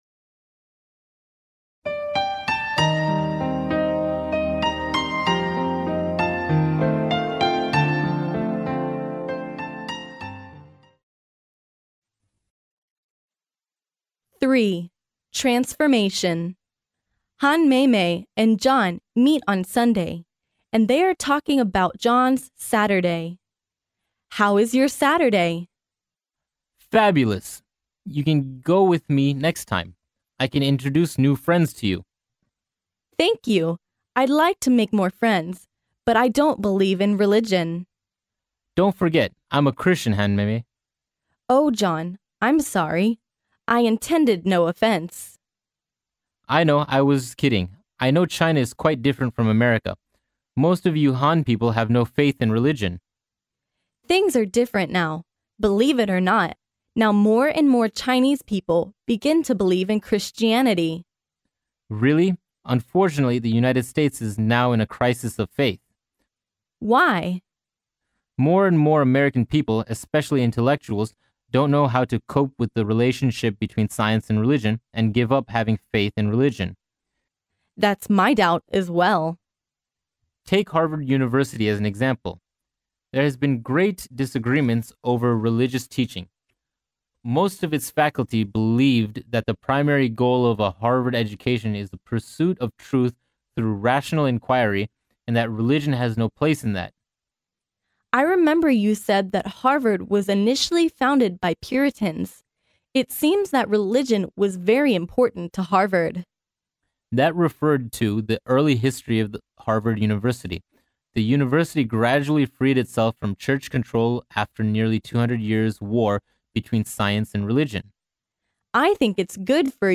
哈佛大学校园英语情景对话03：改头换面（mp3+中英）